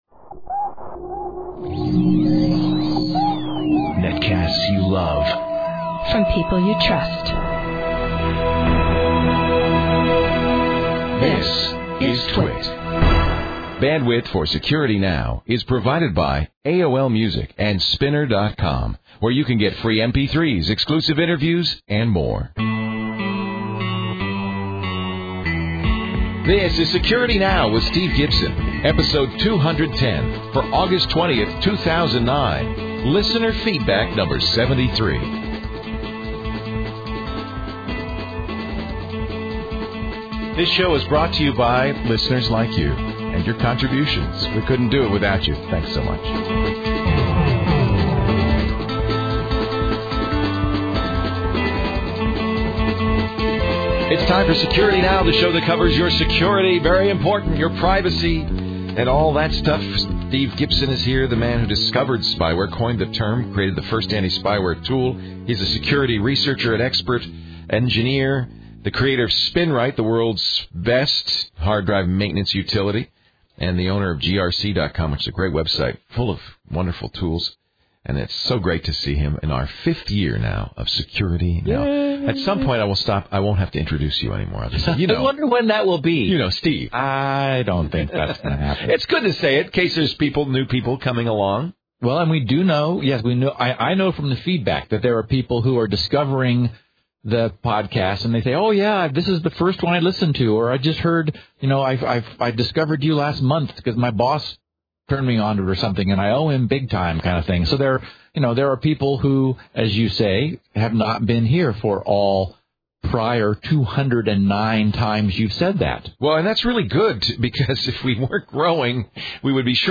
Description: Steve and Leo discuss the week's major security events and discuss questions and comments from listeners of previous episodes. They tie up loose ends, explore a wide range of topics that are too small to fill their own episode, clarify any confusion from previous installments, and present real world 'application notes' for any of the security technologies and issues we have previously discussed.